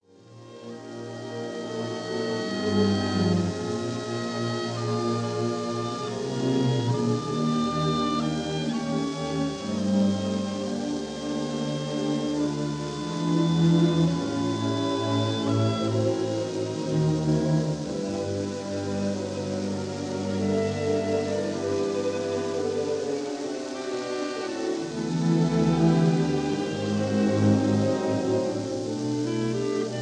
This is a 1930 recording